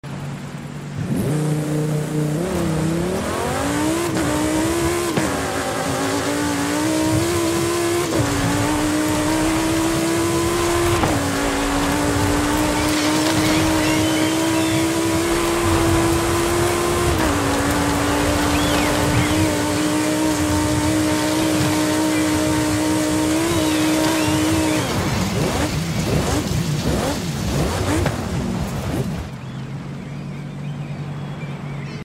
2020 Lamborghini Huracan EVO Off Road Sound Effects Free Download